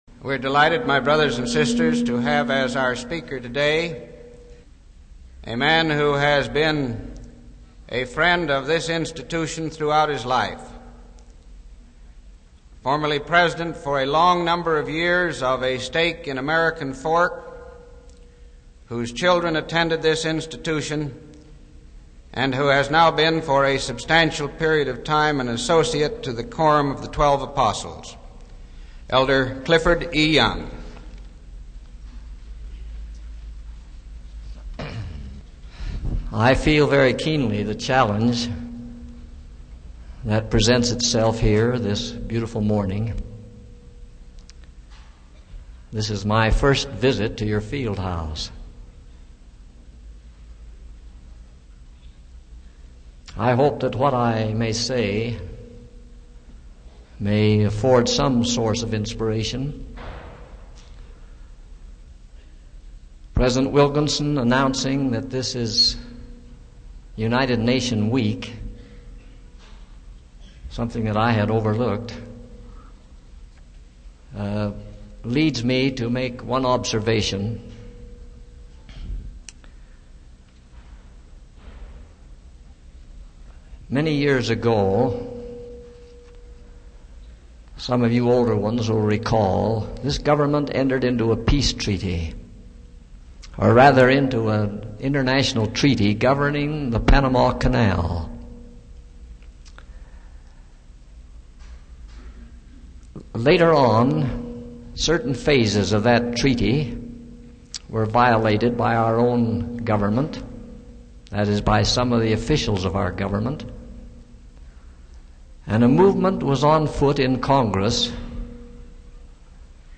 Home > Speeches > Clifford E. Young > Personal Integrity